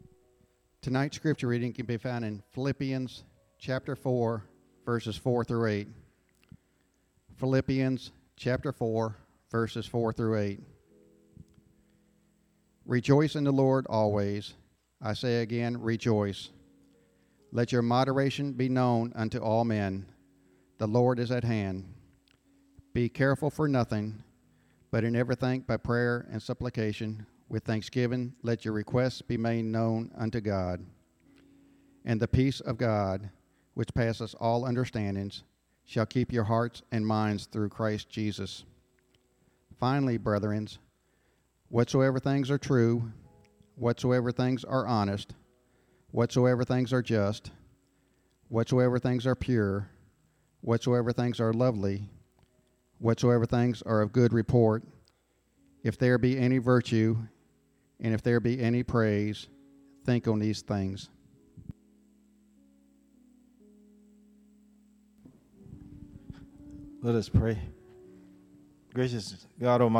Sermons (2017)
Evening Service